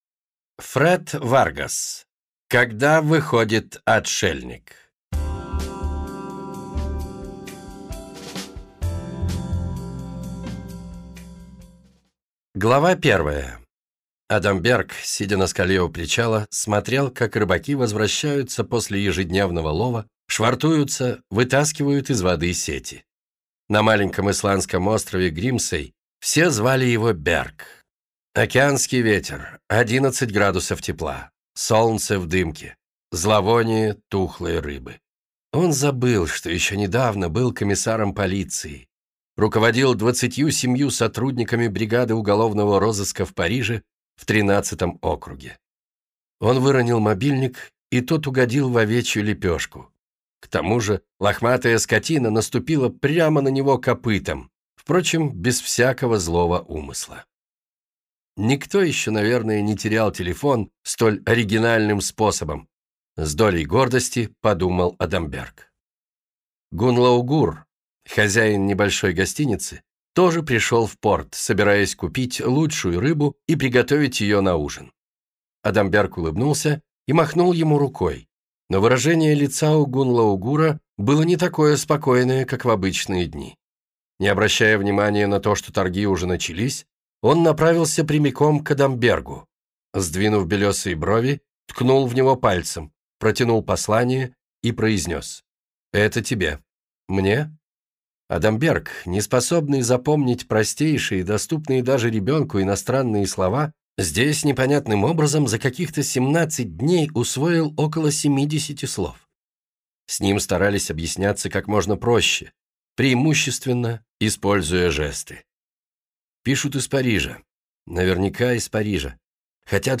Аудиокнига Когда выходит отшельник - купить, скачать и слушать онлайн | КнигоПоиск